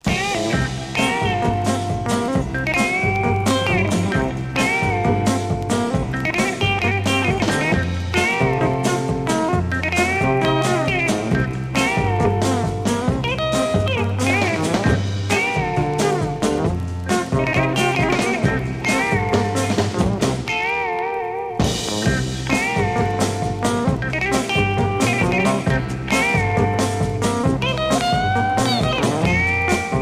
Rock instrumental Quatrième EP retour à l'accueil